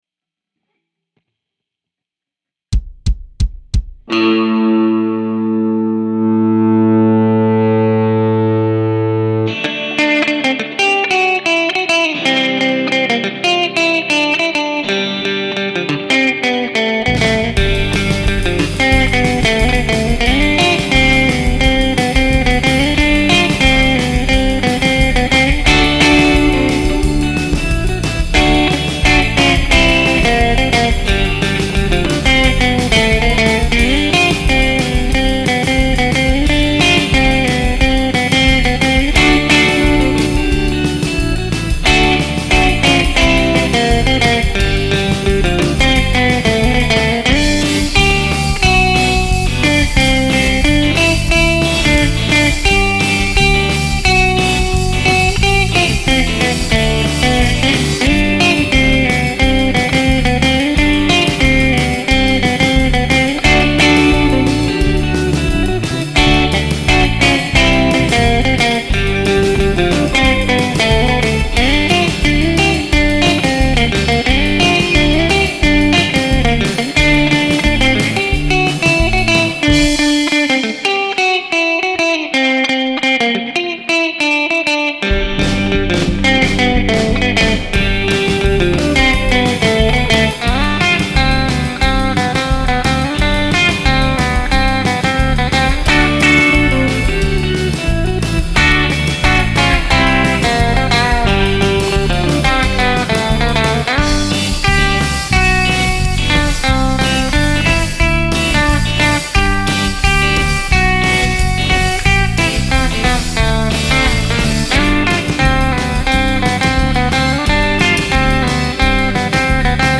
New Jazzmaster
I switched pickups between middle and bridge which are the two positions I use most
That bridge pickup really cuts through